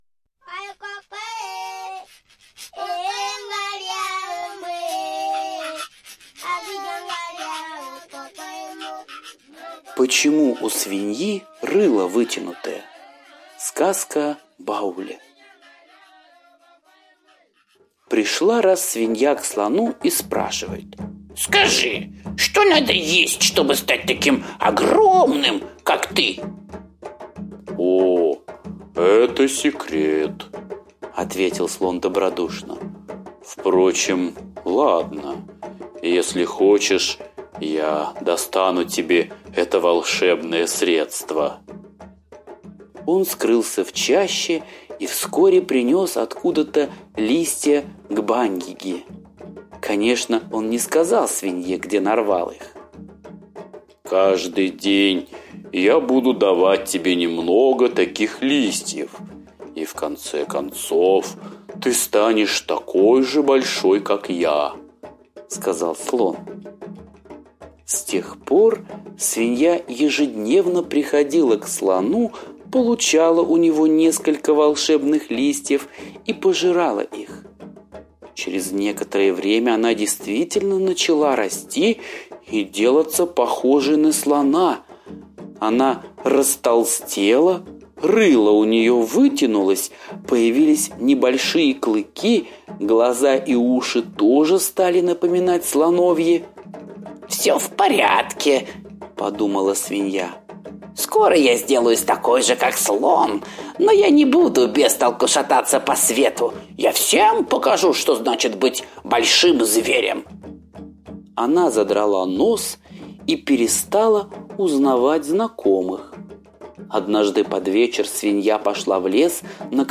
Слушайте Почему у Свиньи рыло вытянутое - восточная аудиосказка.